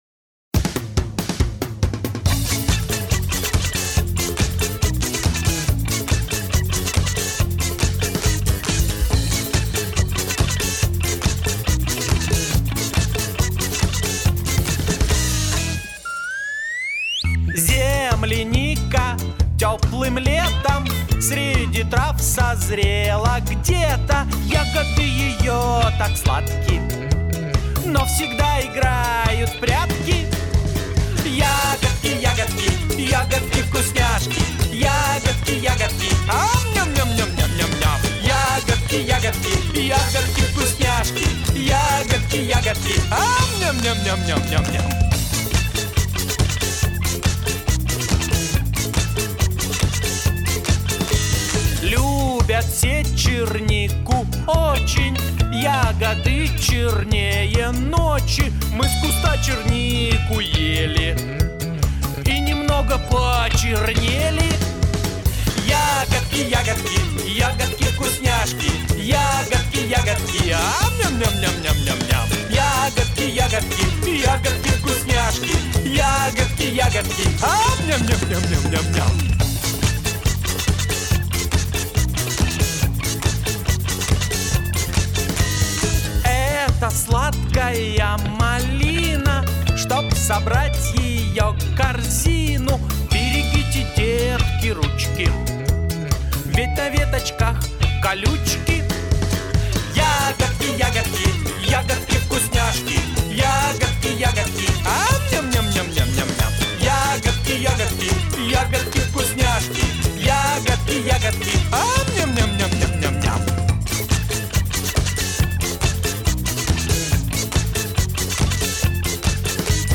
Трактор роет яму